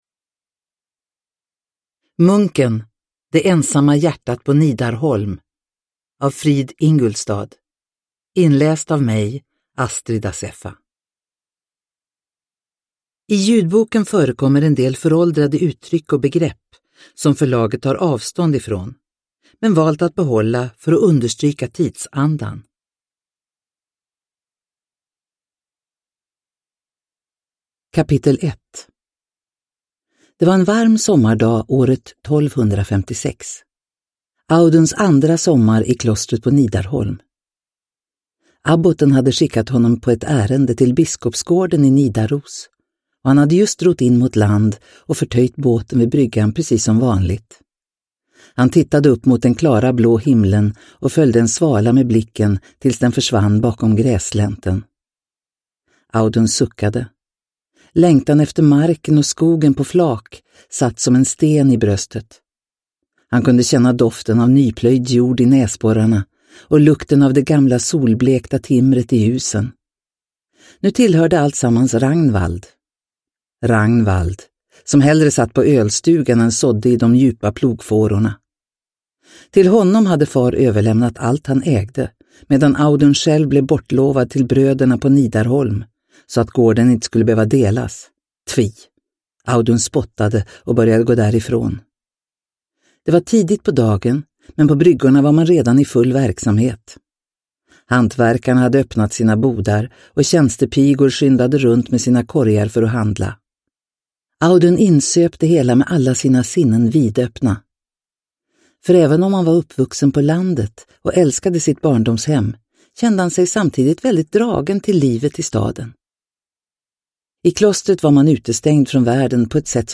Munken – det ensamma hjärtat på Nidarholm – Ljudbok – Laddas ner
Uppläsare: Astrid Assefa